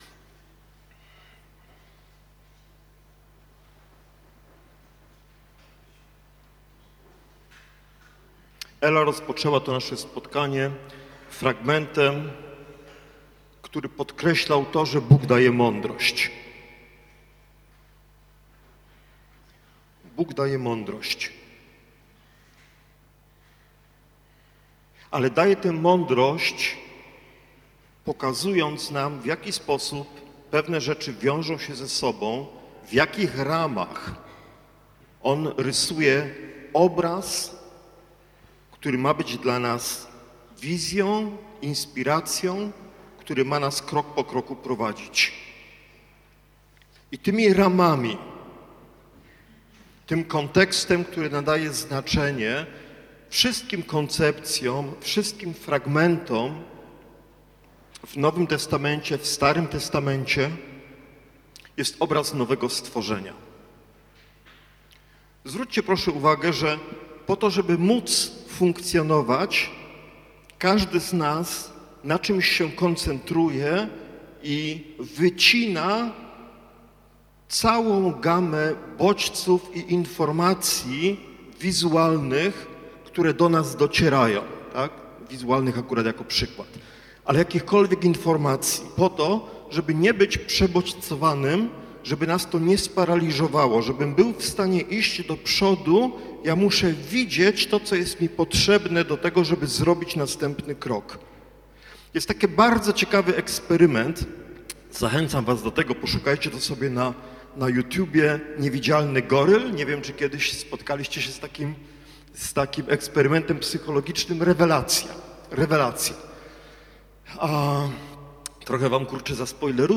Praktyczne Rodzaj Usługi: Nauczanie niedzielne Tematy